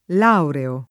laureo [ l # ureo ]